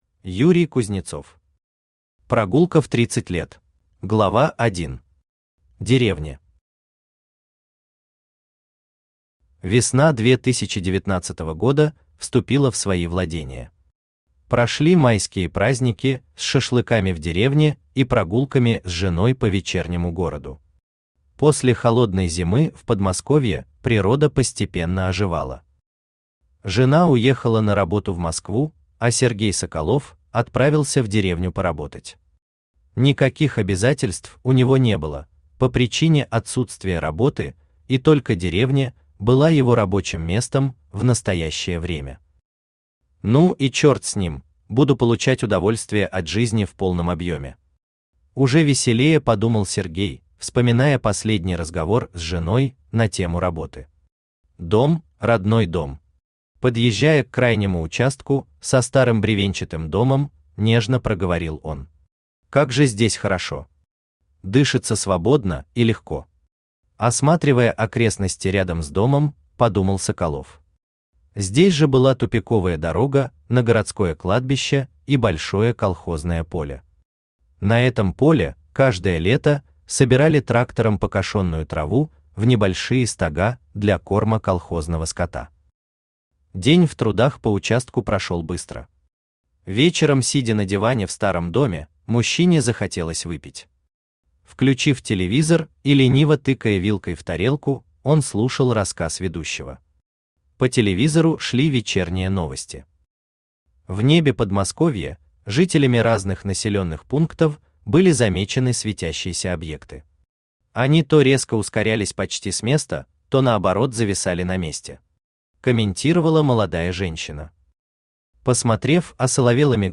Аудиокнига Прогулка в 30 лет | Библиотека аудиокниг
Aудиокнига Прогулка в 30 лет Автор Юрий Юрьевич Кузнецов Читает аудиокнигу Авточтец ЛитРес.